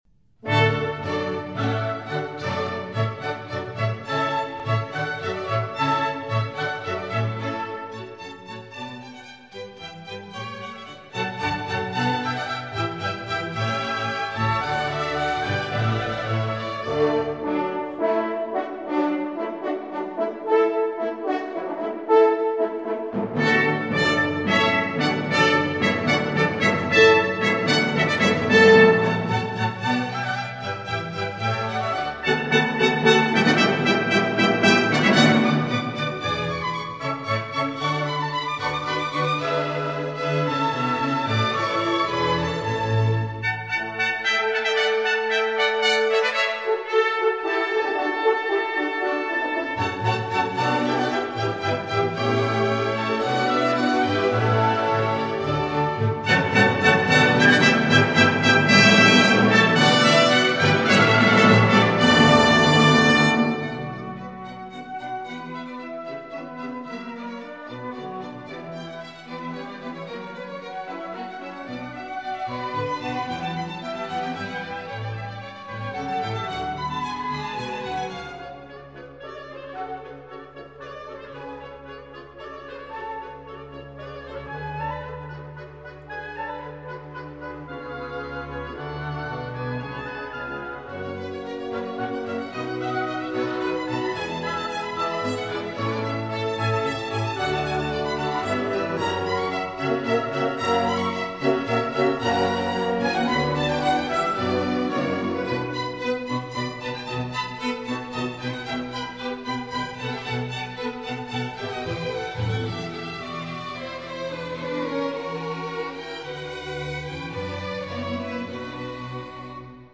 Jų muzika, kaip ir daugelio Hendelio kūrinių, didinga, įspūdinga.